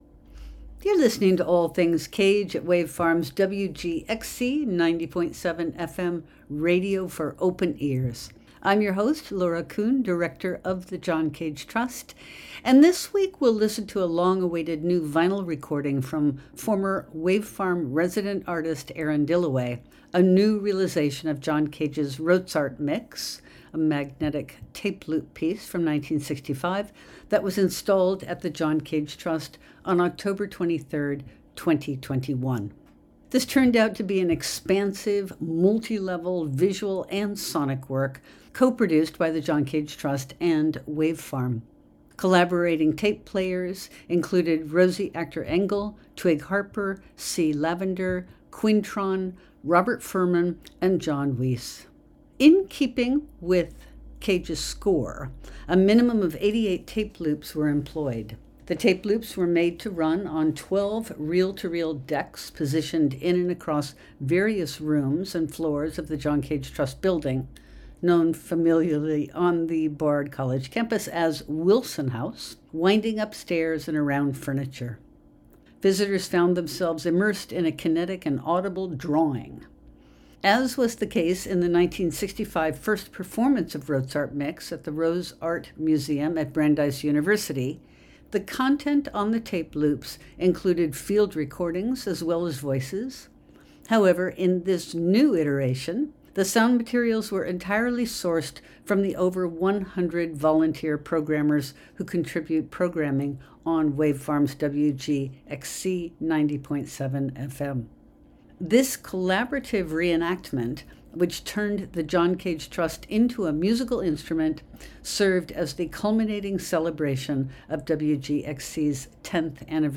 Six hours of 12 individually amplified reel-to-reel tape machines, placed around multiple floors of a house, playing 88 tape loops spliced together by 5 to 175 splices, created an overwhelming and joyous environment of cacophonous sound.